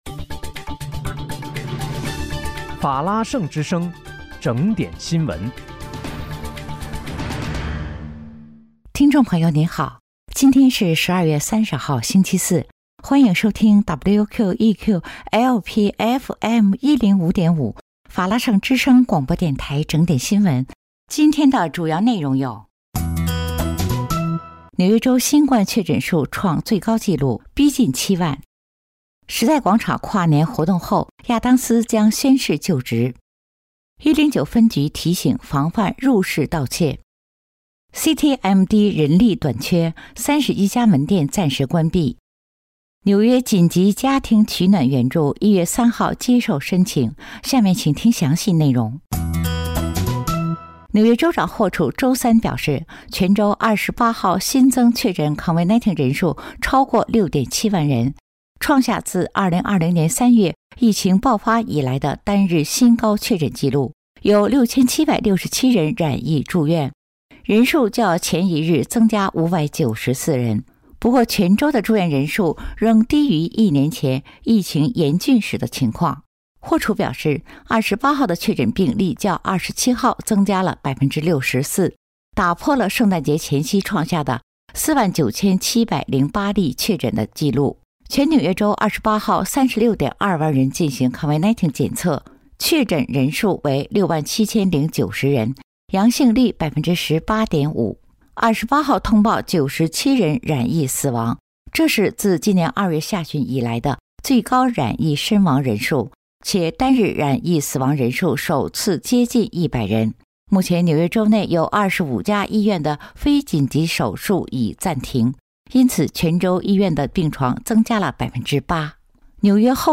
12月30日（星期四）纽约整点新闻